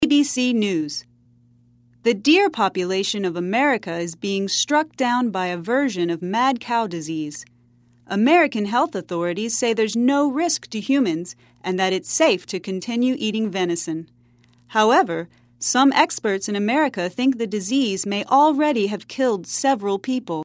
在线英语听力室赖世雄英语新闻听力通 第2期:鹿只死于狂牛症的听力文件下载,本栏目网络全球各类趣味新闻，并为大家提供原声朗读与对应双语字幕，篇幅虽然精短，词汇量却足够丰富，是各层次英语学习者学习实用听力、口语的精品资源。